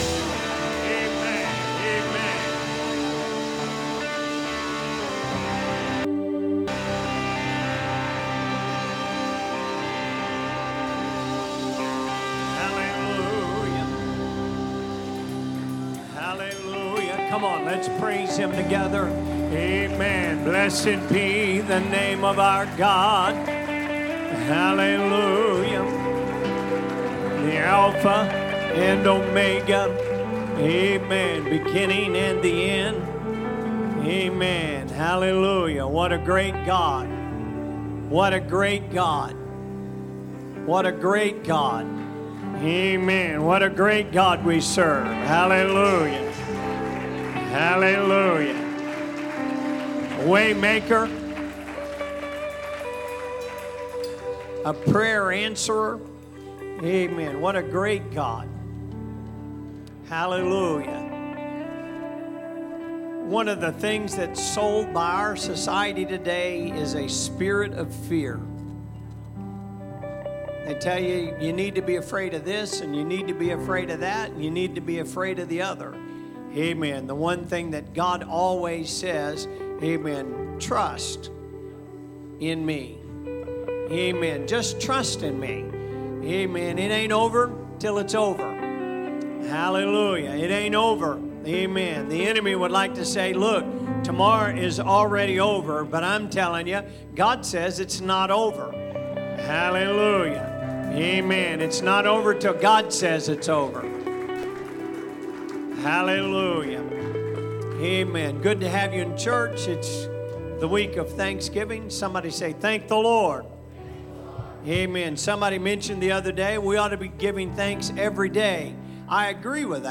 Sermons | Elkhart Life Church
Sunday Service - Part 21